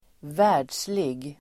Ladda ner uttalet
världslig adjektiv, worldly Uttal: [²v'ä:r_dslig]